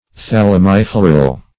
Search Result for " thalamifloral" : The Collaborative International Dictionary of English v.0.48: Thalamifloral \Thal`a*mi*flo"ral\, Thalamiflorous \Thal`a*mi*flo"rous\, a. [See Thalamus , and Floral .]